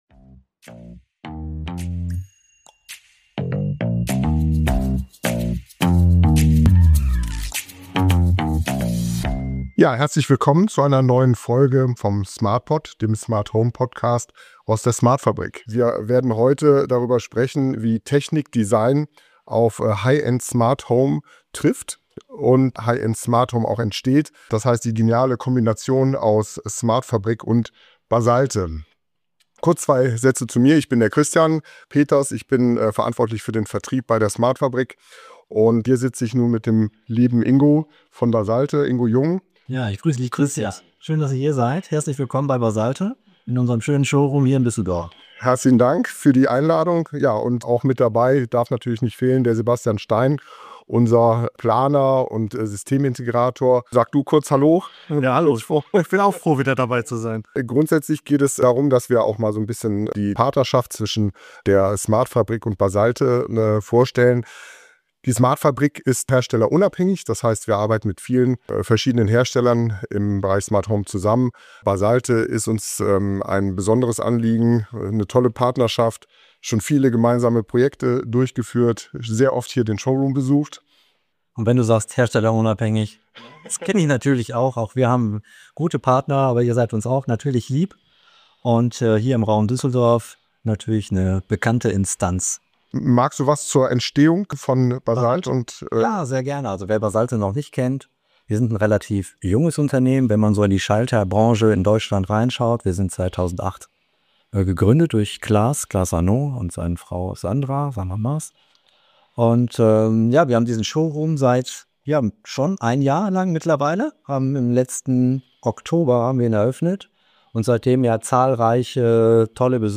direkt aus dem Basalte Showroom in Düsseldorf. Im Mittelpunkt steht die Partnerschaft zwischen smartfabrik und Basalte – und warum sie für Bauherren, Architekten und Interior Designer einen echten Mehrwert bietet.